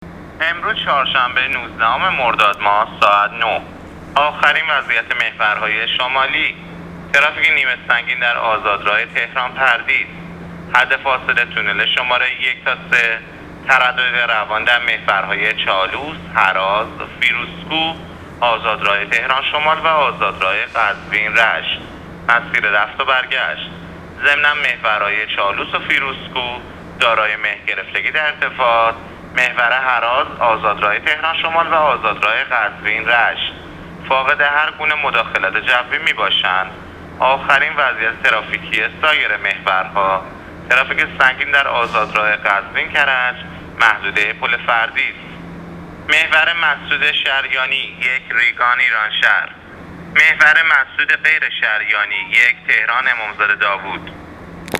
گزارش رادیو اینترنتی از آخرین وضعیت ترافیکی جاده‌ها تا ساعت ۹ نوزدهم مردادماه؛